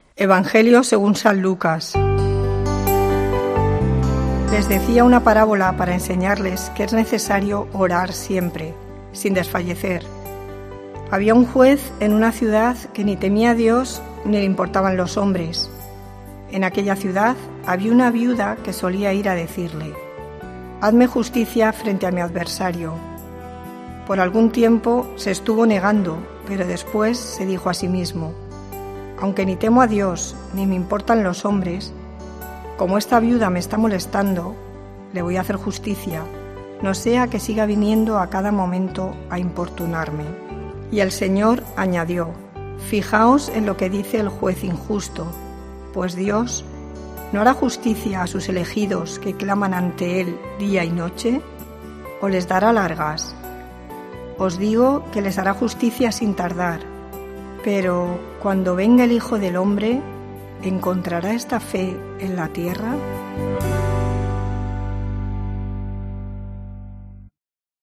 Lectura del santo evangelio según san Lucas 18,1-8:En aquel tiempo, Jesús, para explicar a sus discípulos cómo tenían que orar siempre sin desanimarse,...